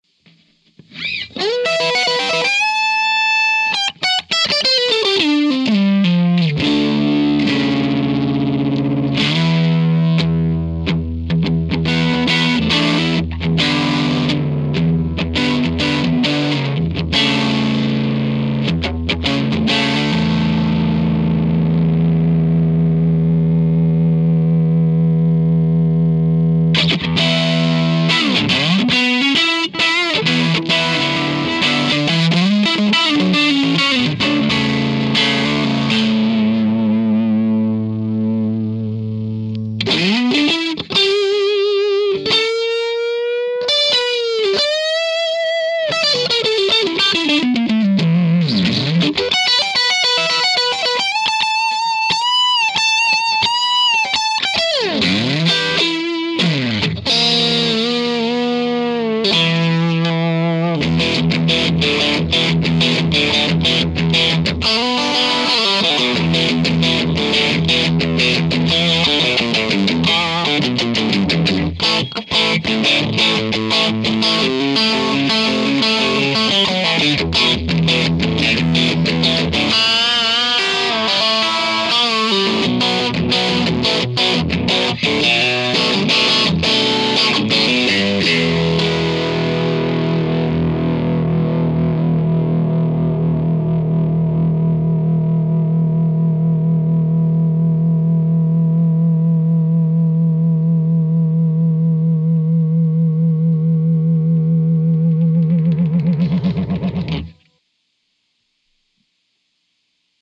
As requested, boosted clippage. The settings were the same for both clips. Boost on, bass at 6, Mid and treble at 7, volume at 7, master at 9.
Once again the setup is the same as before with a 2x12 with tone tubby ceramics in it.